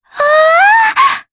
Mediana-Vox_Happy4_jp.wav